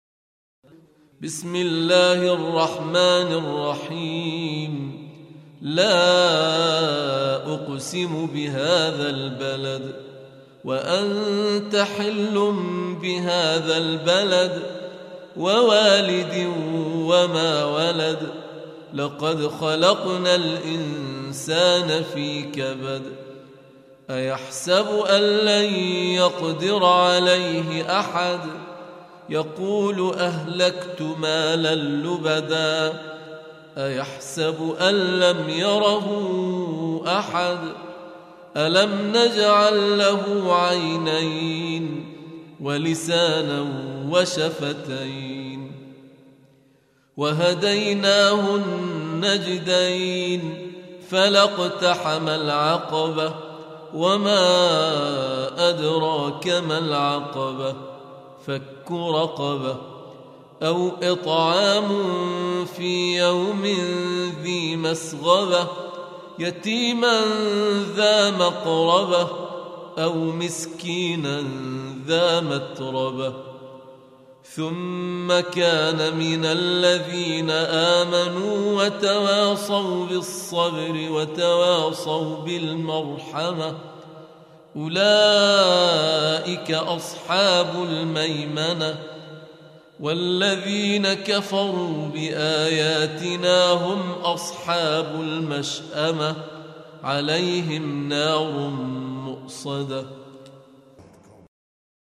Surah Sequence تتابع السورة Download Surah حمّل السورة Reciting Murattalah Audio for 90. Surah Al-Balad سورة البلد N.B *Surah Includes Al-Basmalah Reciters Sequents تتابع التلاوات Reciters Repeats تكرار التلاوات